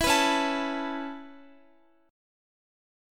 Listen to Dbm7#5 strummed